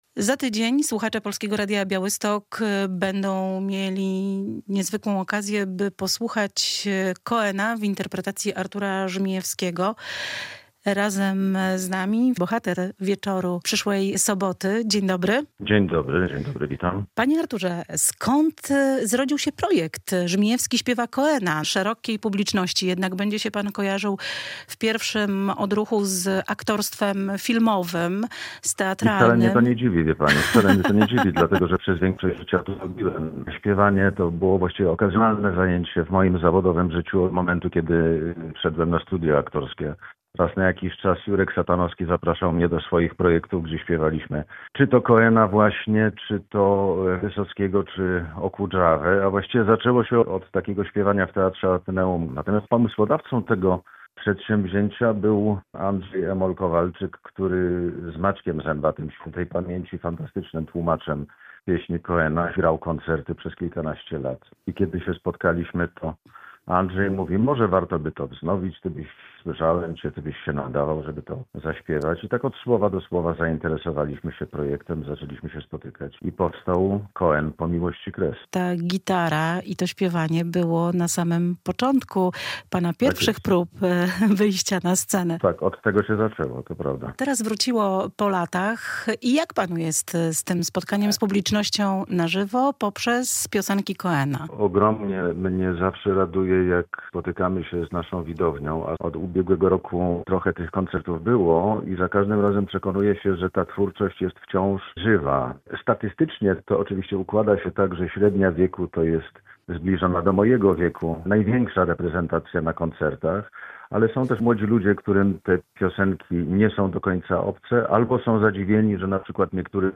Z Arturem Żmijewskim rozmawia